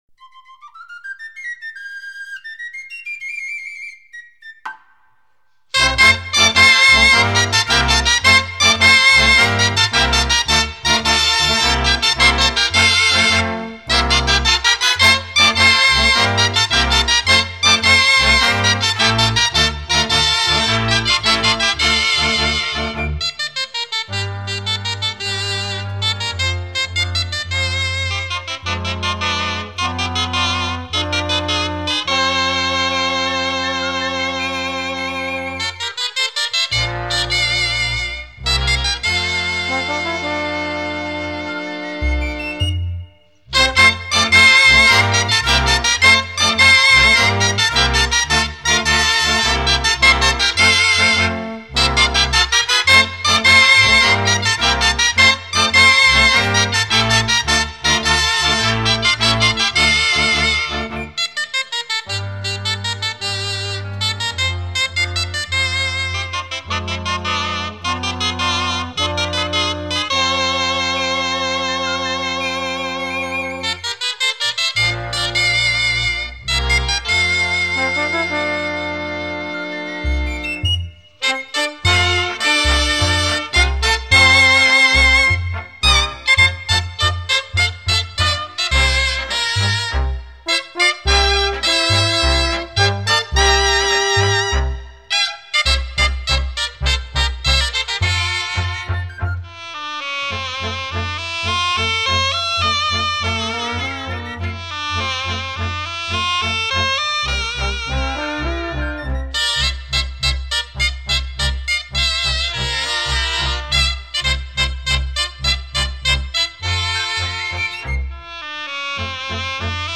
La sardana Platges d’Arenys va ser composta per Carles Rovira i Reixach (Sabadell, 23 d’abril de 1929- 16 de febrer de 2006) que malgrat la seva tasca professional, químic expert en fundació, sempre trobà espais per conrear la seva gran afecció: la composició de sardanes.